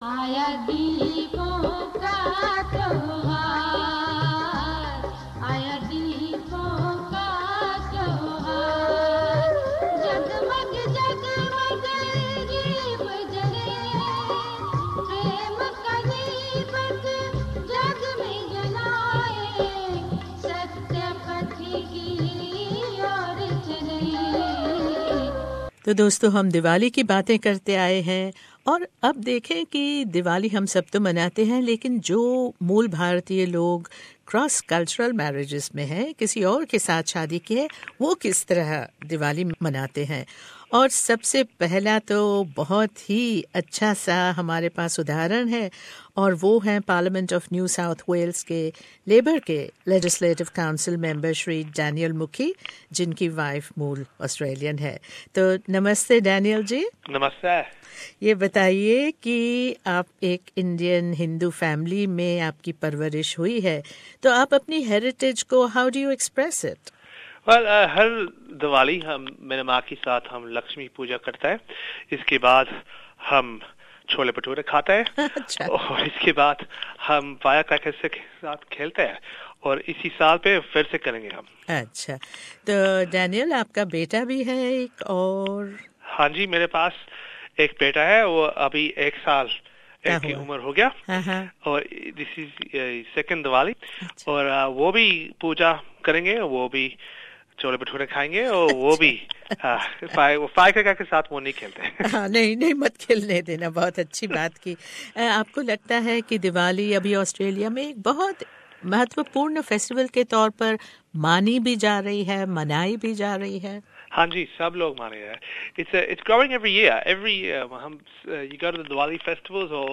भारतीय तो मनाते ही हैं दिवाली बड़े हर्षोल्लास के साथ , लेकिन आज आइये देखें कि जिन मूल भारतीयों ने किसी और संस्कृति का जीवन साथी चुना है वो कैसे मानते हैं दिवाली !इस एक्सक्लूसिव भेंटवार्ता में हमने बात की है NSW के पहले मूल भारतीय हिन्दू सांसद डेनियल मुखेय